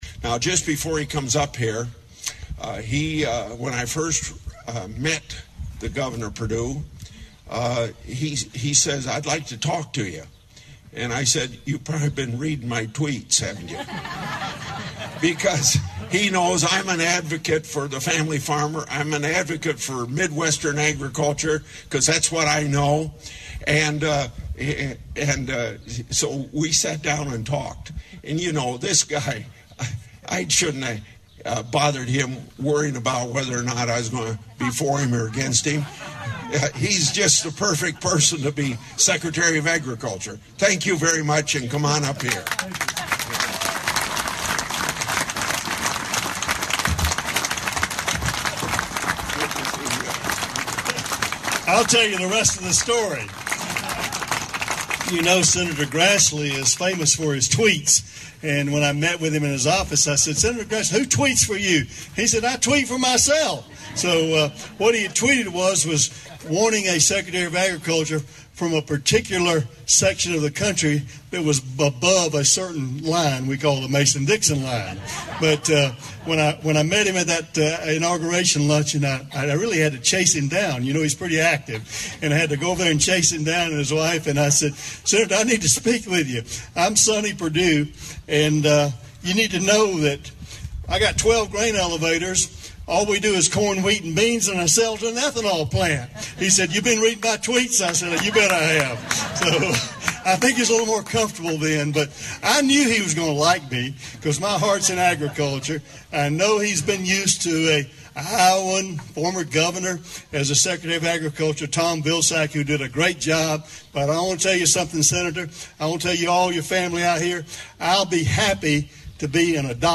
On Friday, US Secretary of Agriculture Sonny Perdue visited Iowa and gave remarks to a crowd of Iowa farmers and others connected to agriculture- our colleagues from the Iowa Agribusiness Radio Network covered the event and what follows is their story and the audio of the Perdue speech. Click on the LISTEN BAR below to hear Iowa Senator Chuck Grassley introduce Secretary Perdue and then Perdue's comments.